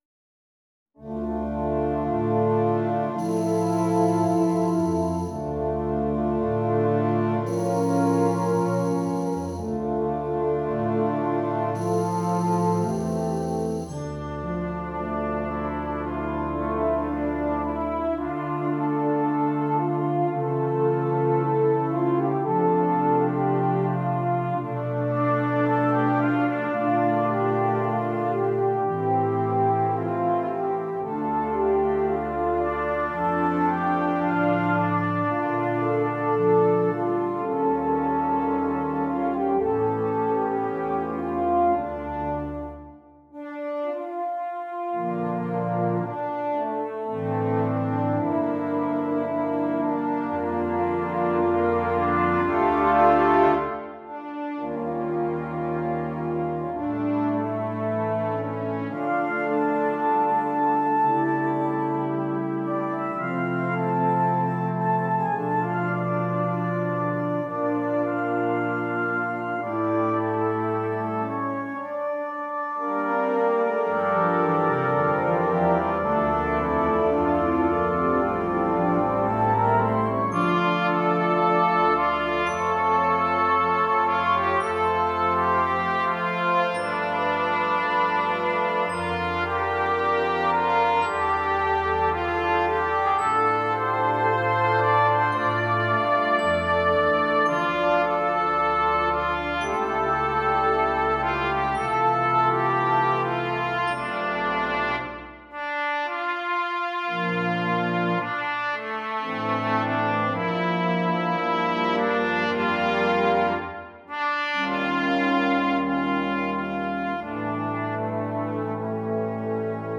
Brass Band
Traditional